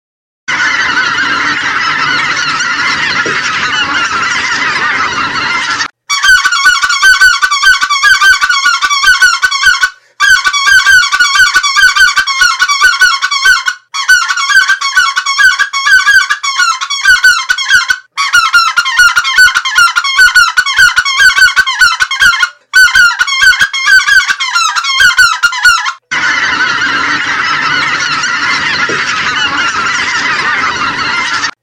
multiple bird sound 2014 redbone